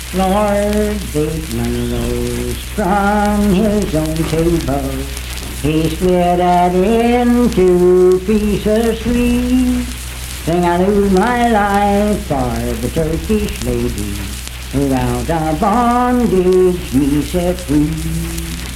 Unaccompanied vocal music
Performed in Sandyville, Jackson County, WV.
Voice (sung)